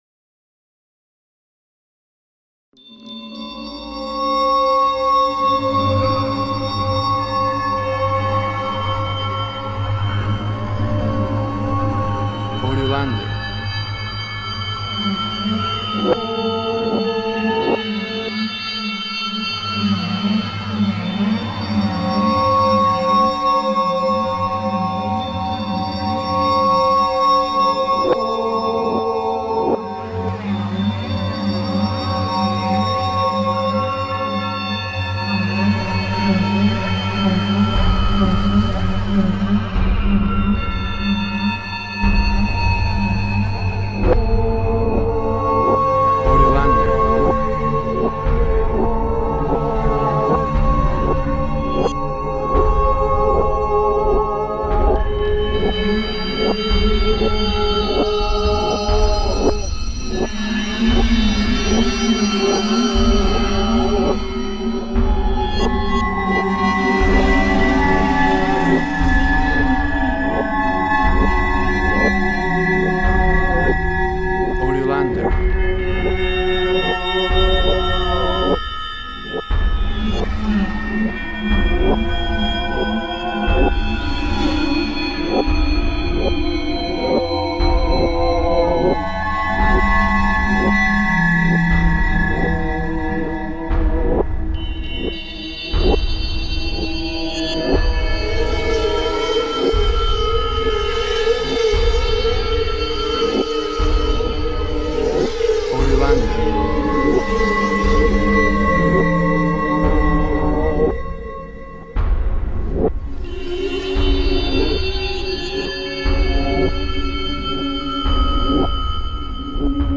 Tempo (BPM) indefinite